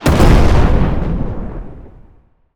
explosion_large_07.wav